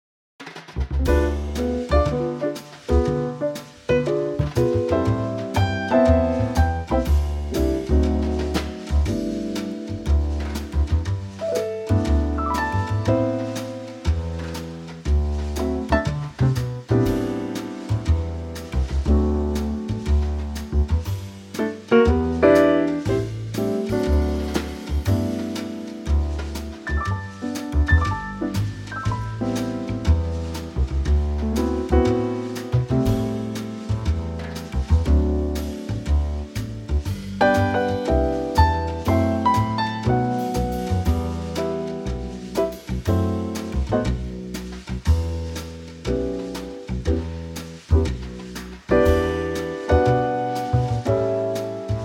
Key - G - vocal range - C# to E
Here's a lovely lightly swinging Trio arrangement.